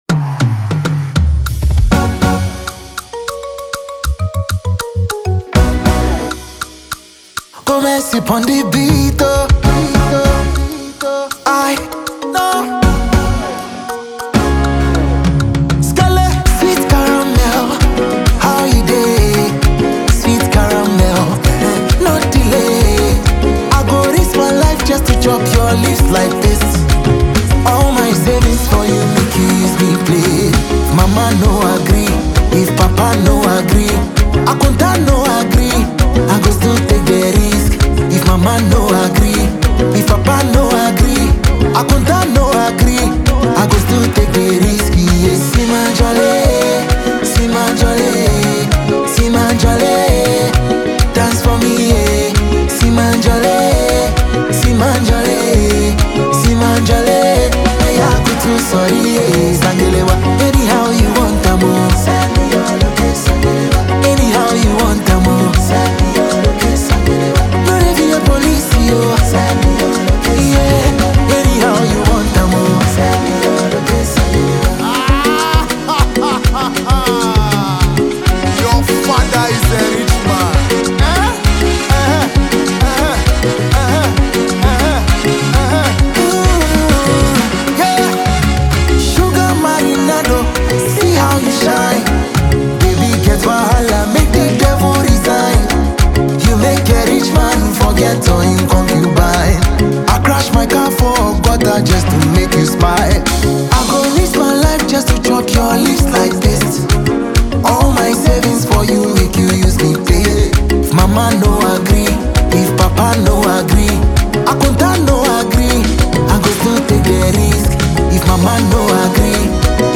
and dedication to crafting high-quality Afro-pop music.